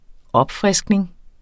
Udtale [ ˈʌbˌfʁεsgneŋ ]